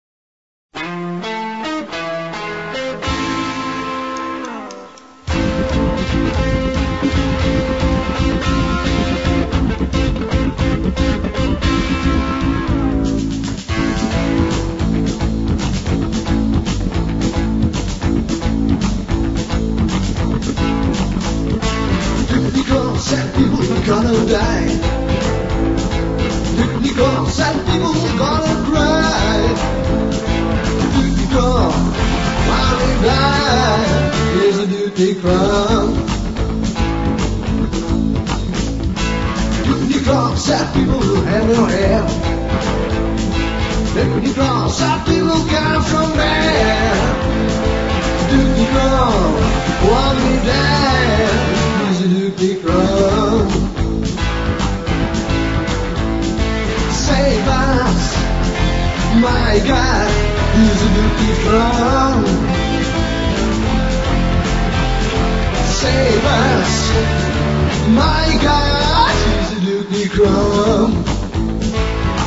Еще немного сайко